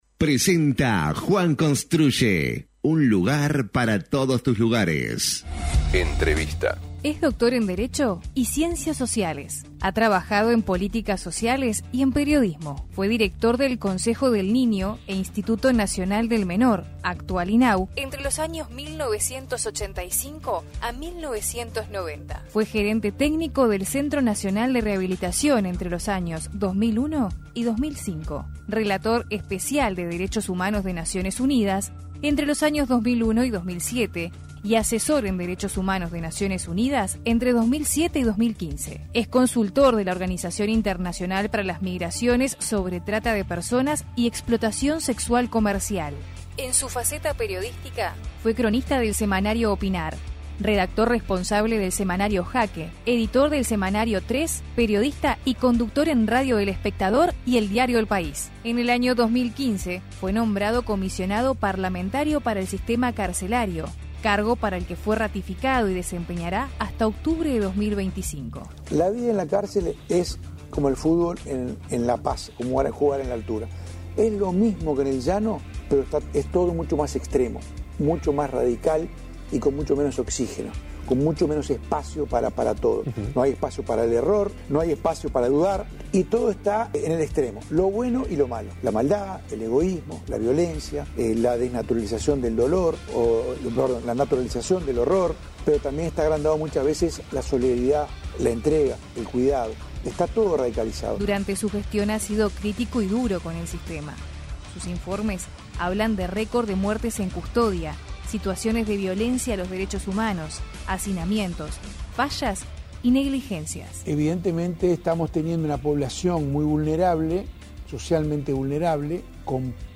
Entervista a Juan Miguel Petit